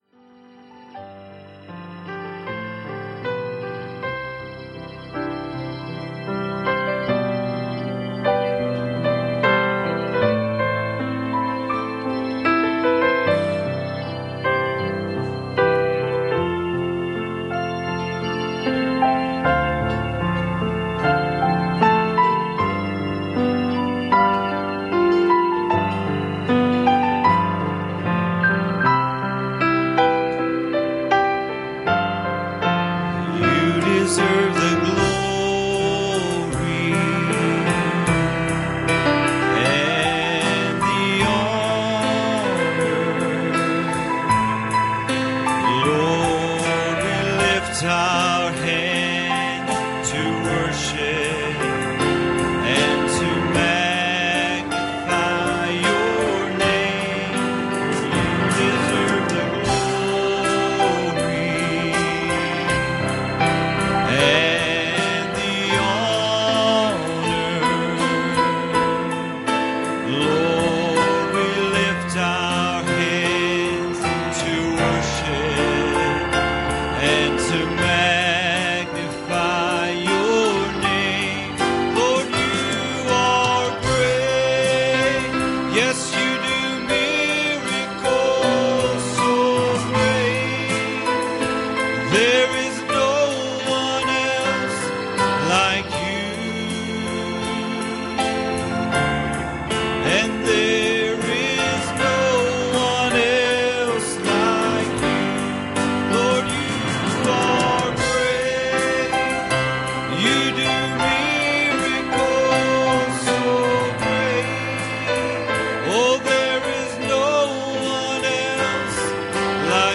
Passage: Mark 7:5 Service Type: Sunday Morning